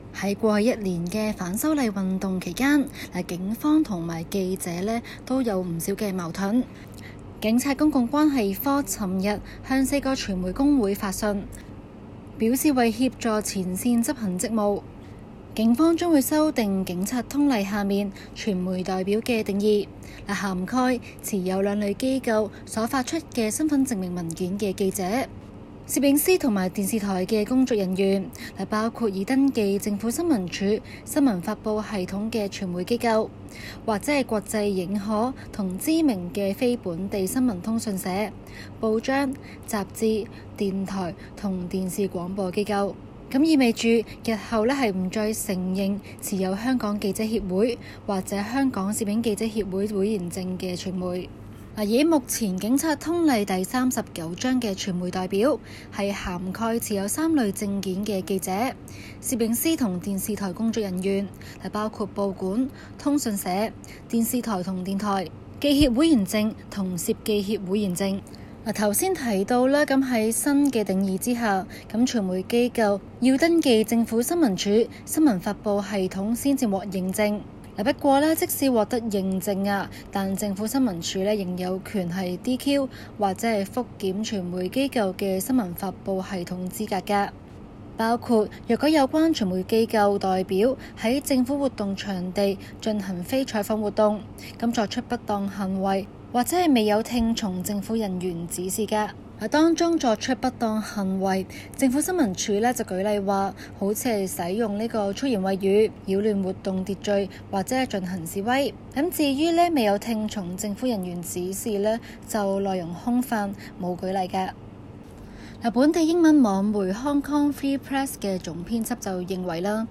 SBS廣東話節目